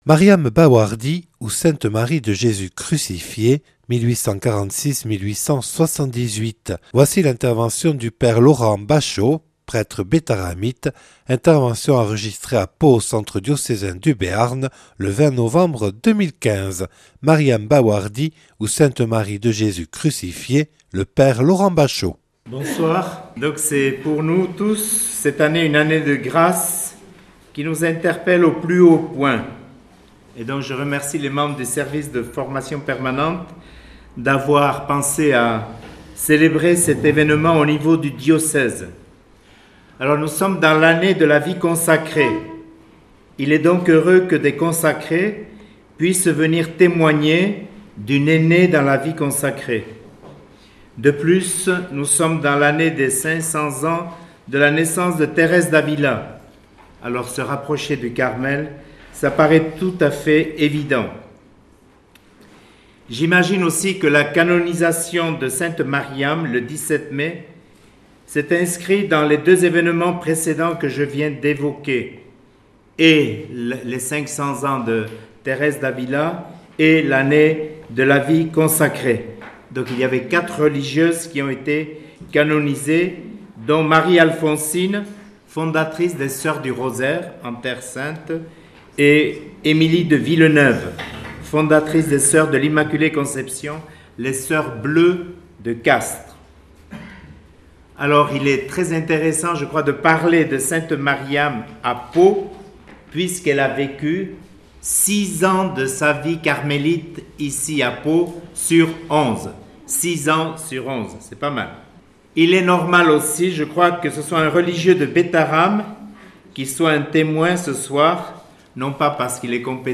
(Enregistré le 20/11/2015 à Pau au Centre diocésain du Béarn).